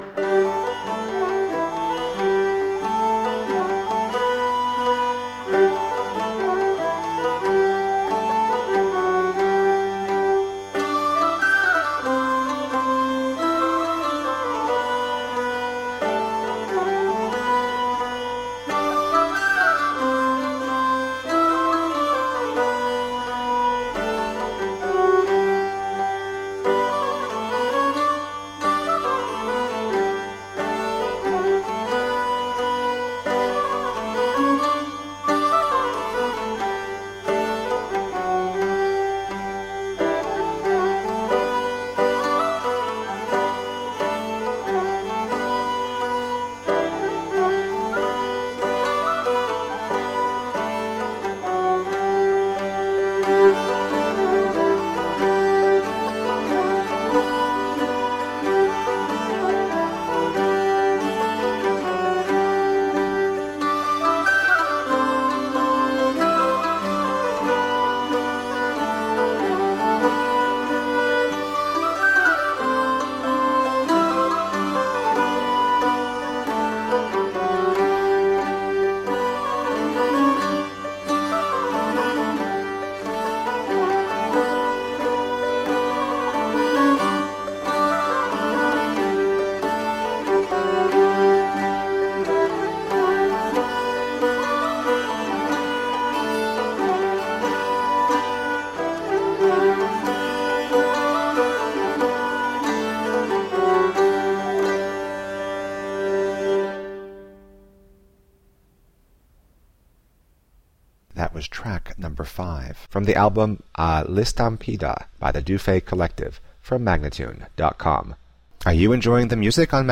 Medieval Dance Music.